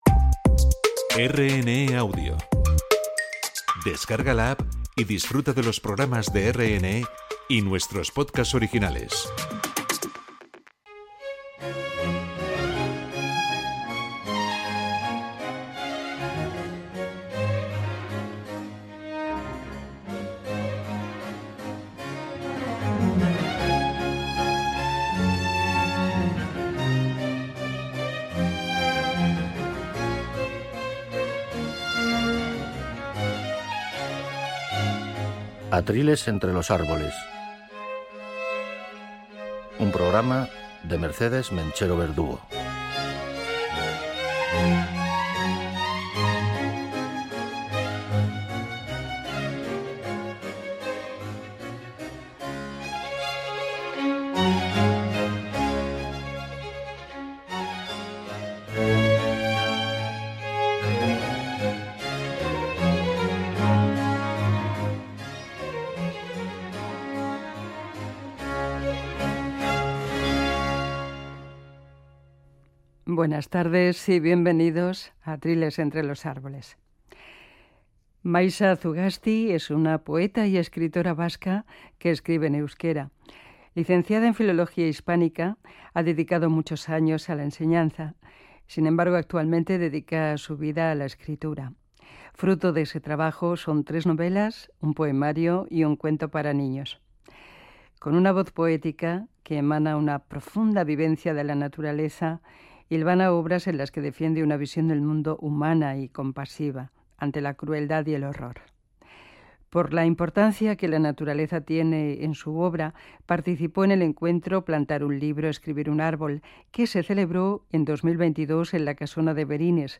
Atriles entre los árboles es un programa sobre naturaleza, en el que se recorren jardines, bosques, parajes en extinción y otros muchos paisajes vegetales del planeta. Cada uno de estos parajes tendrá un paisaje sonoro que irá acompañando el itinerario.